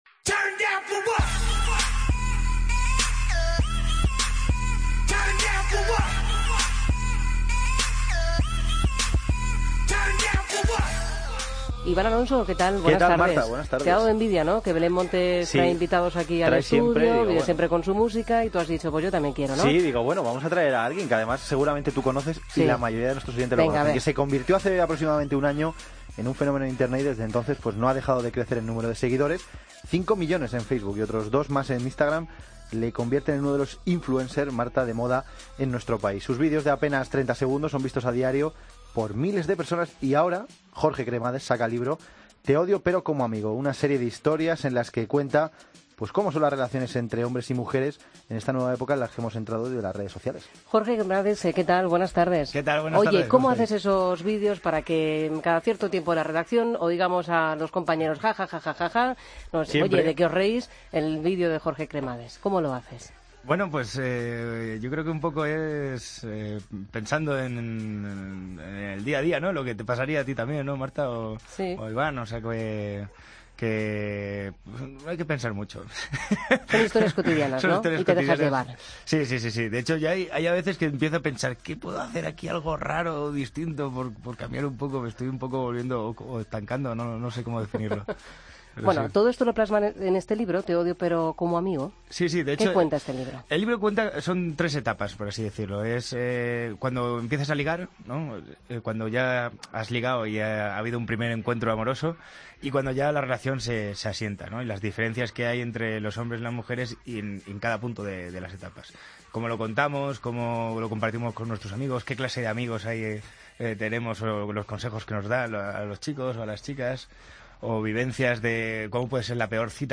Entrevista a Jorge Cremades en 'La Luz de La Linterna'